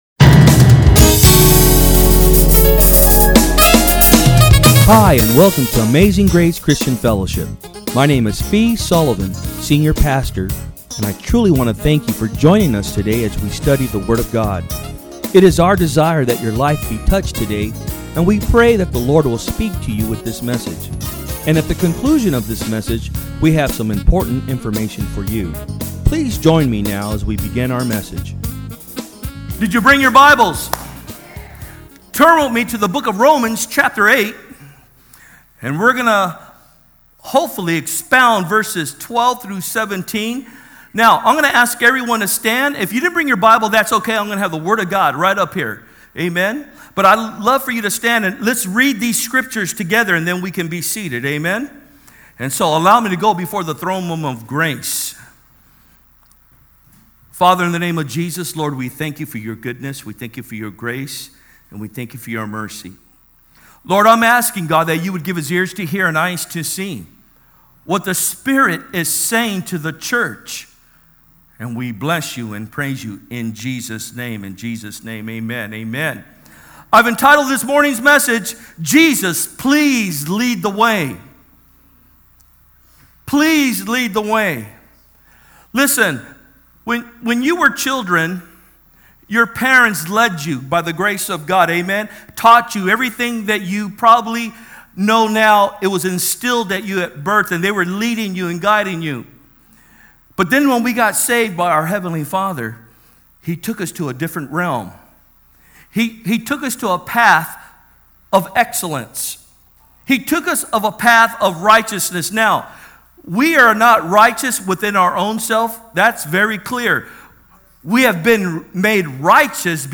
From Service: "Sunday Am"